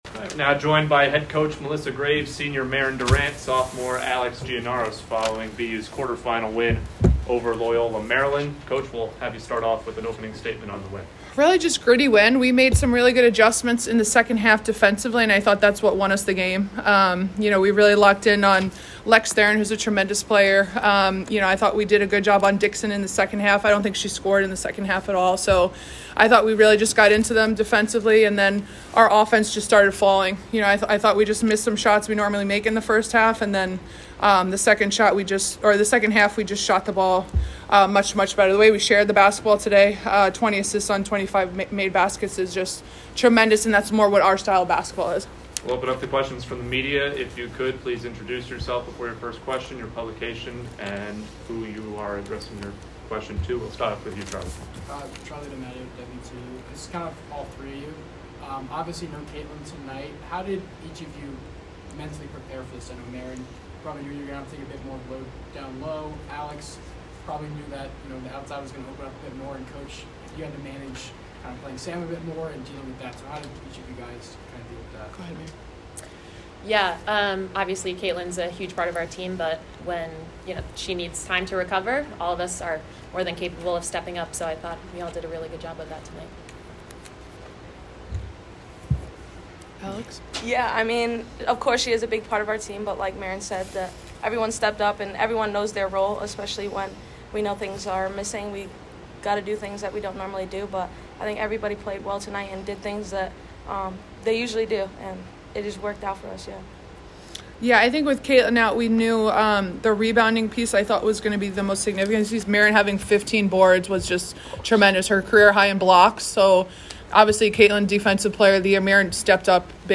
PL Quarterfinal Postgame Press Conference